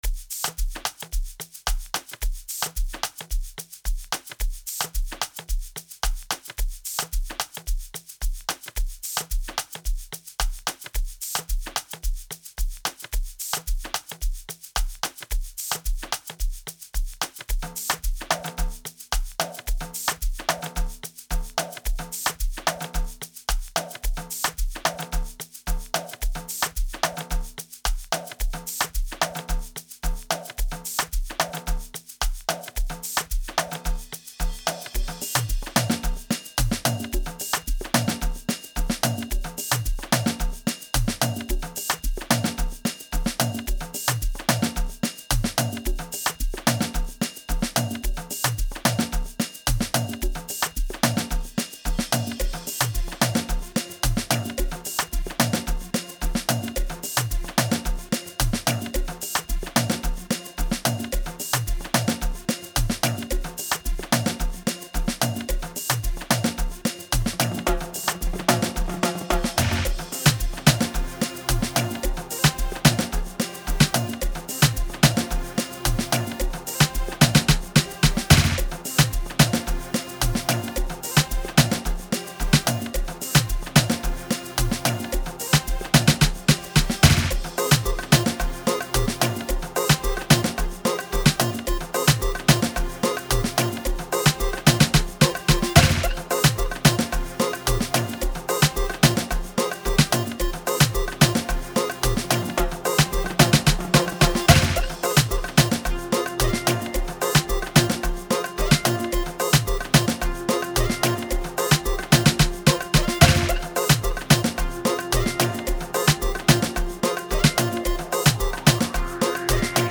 they have proven to be a general of the Amapiano movement